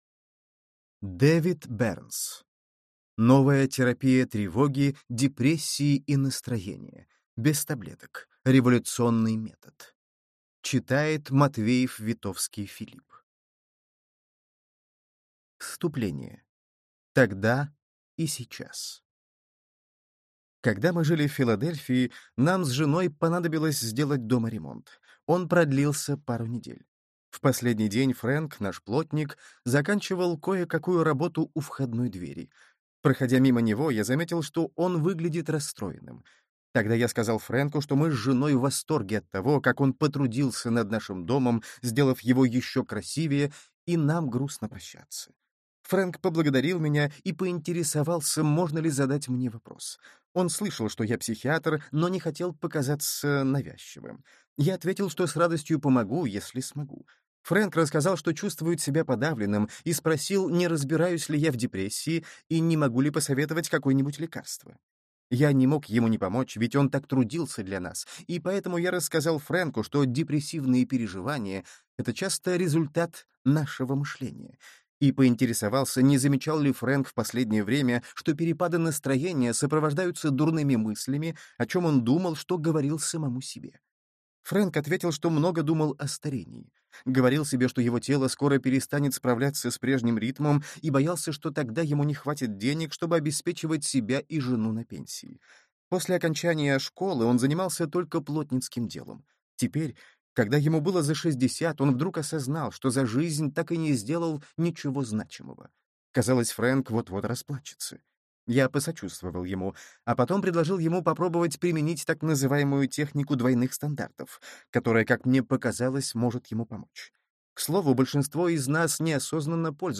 Аудиокнига Новая терапия тревоги, депрессии и настроения. Без таблеток. Революционный метод | Библиотека аудиокниг